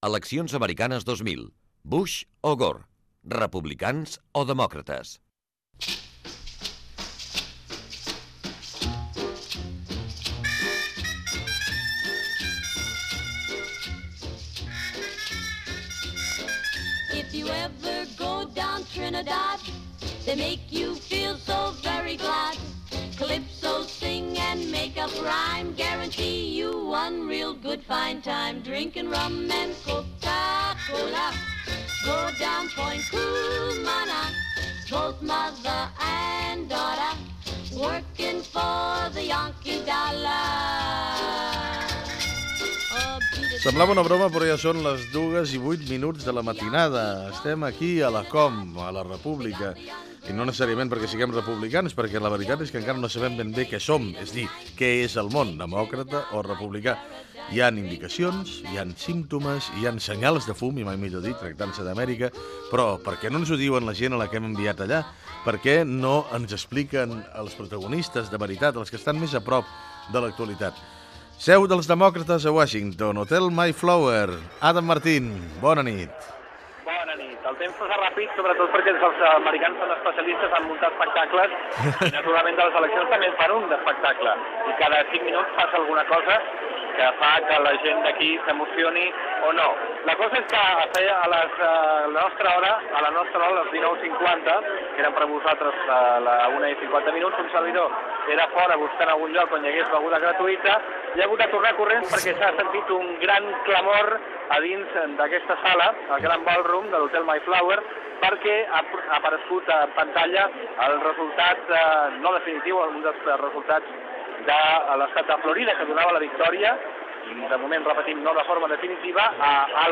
Info-entreteniment
FM
Fragment extret de l'arxiu sonor de COM Ràdio.